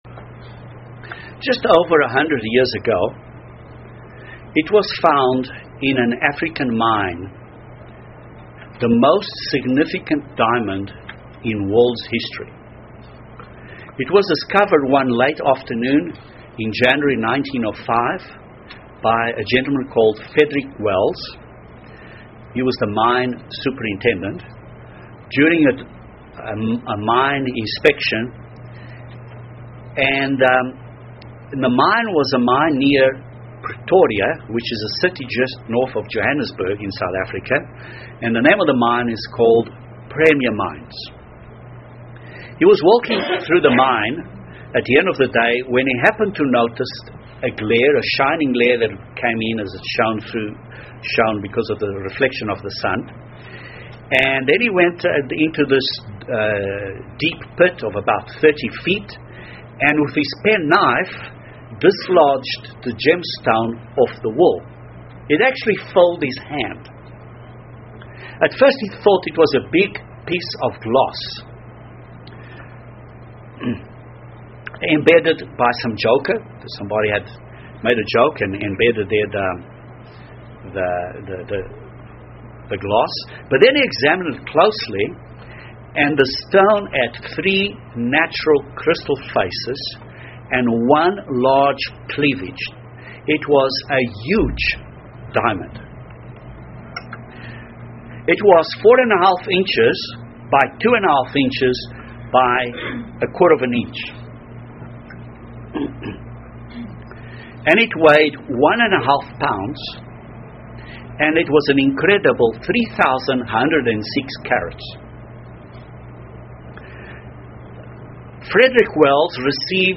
This sermon analyses four caracteristics used to determine the value of a diamond. They are called the 4 C's. These 4 characteristics may also be used to determine the value of God's Spiritual diamonds.